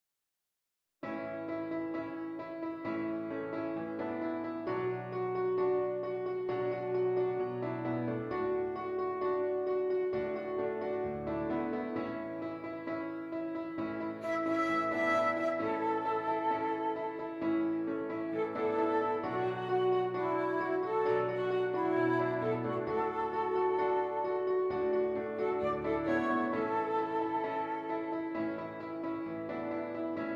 Flute Solo with Piano Accompaniment
A Major
Moderate Rock